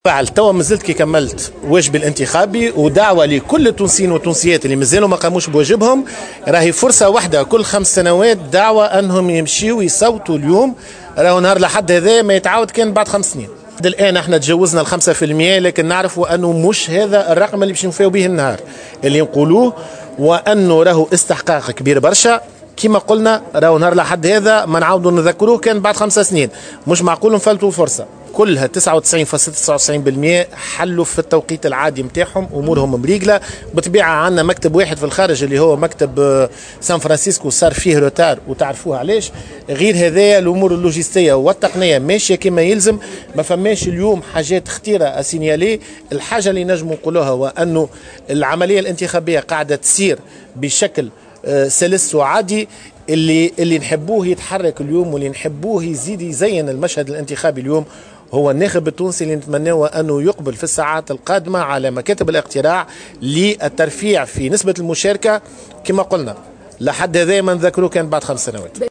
قال رئيس العليا المستقلة للانتخابات نبيل بفّون، في تصريح لمراسلة "الجوهرة أف أم" اليوم الأحد، إن نسبة اقبال الناخبين تجاوزت 5 % إلى حد الآن ( ظهر اليوم). وتوقّع رئيس الهيئة ارتفاع هذه النسبة خلال الساعات المقبلة، داعيا الناخبيين للإقبال على الانتخاب، وذلك على هامش قيامه بعملية التصويت بمدرسة حي الرياض في دائرة باردو. وأكد ان سير عملية الاقتراع يتم بشكل سلس ولم يتم تسجيل أي تجاوز خطير.